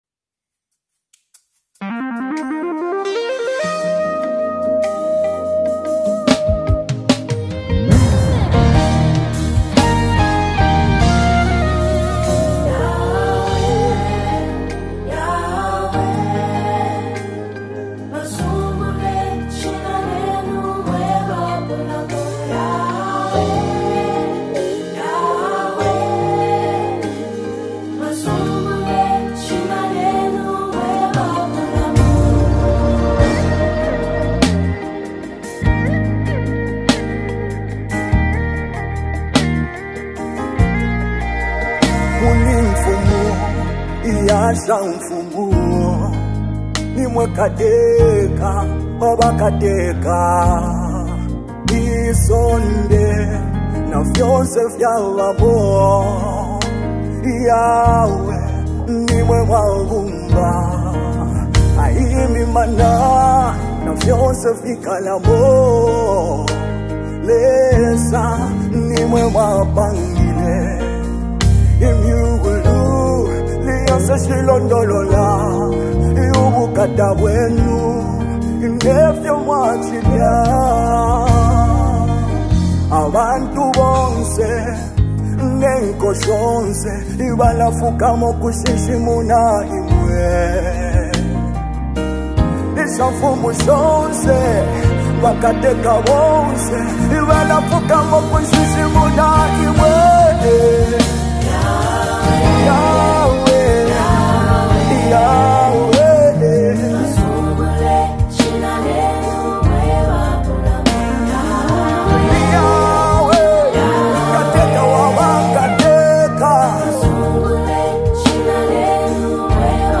2024 Best Zambian Worship song